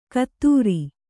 ♪ kaattūri